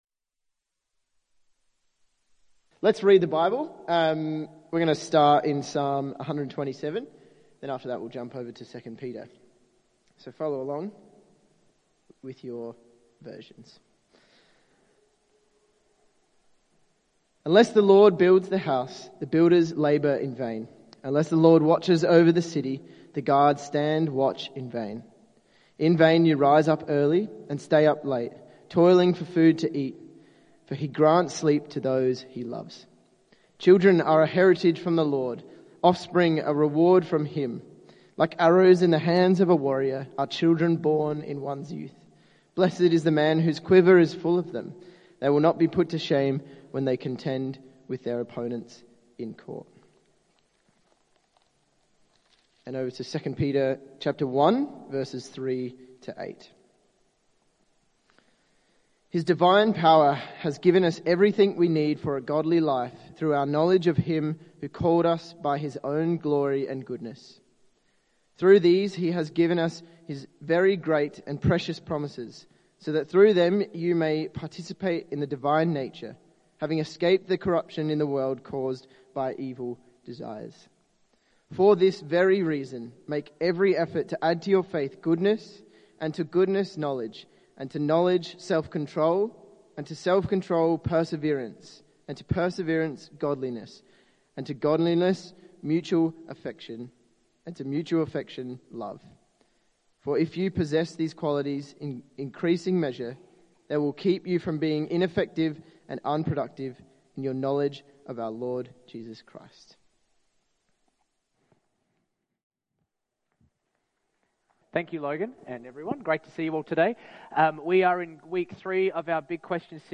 Parenting brings an undeniable joy, but the joy comes with complexities and frustrations. The talk asks the question whether children can truly provide ultimate meaning and purpose, highlighting challenges such as strained relationships, infertility, and disappointment.